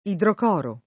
idrocoro [ i drok 0 ro ]